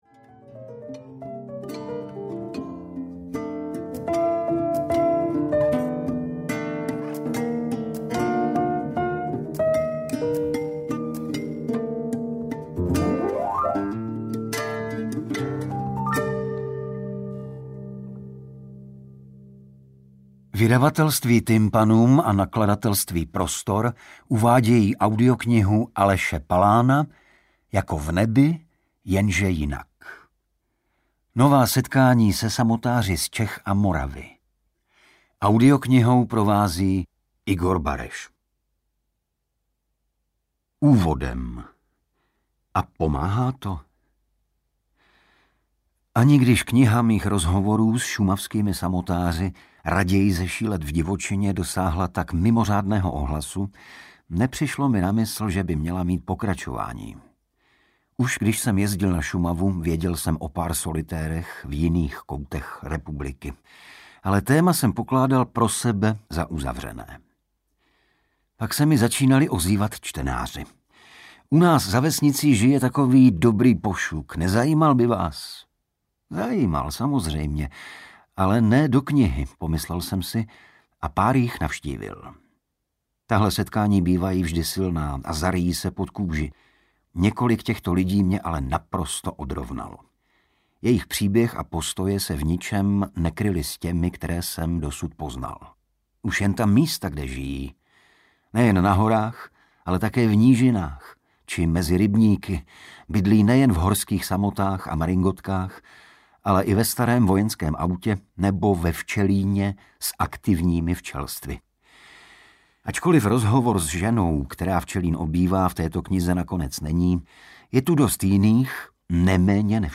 Jako v nebi, jenže jinak audiokniha
Ukázka z knihy